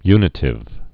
(ynĭ-tĭv, y-nī-)